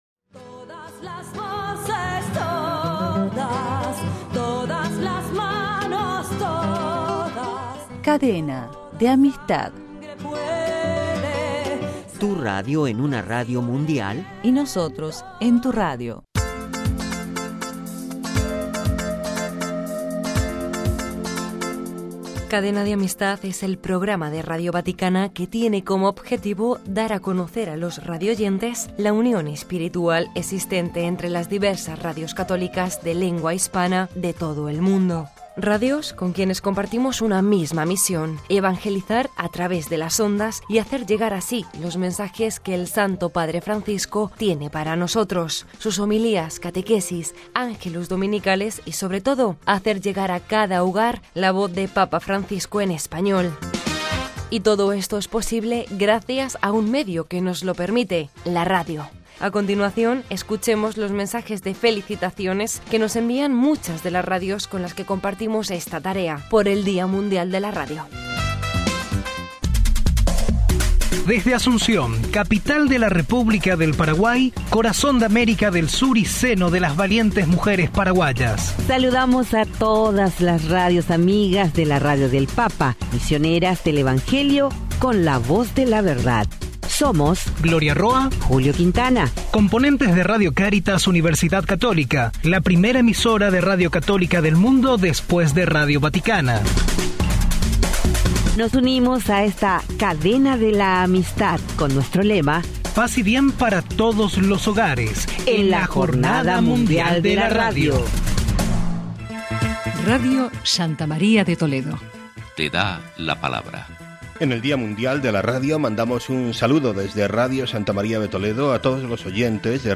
A continuación, escuchemos los mensajes de felicitaciones que nos envían muchas de las radios con las que compartimos esta tarea, por el Día Mundial de la Radio.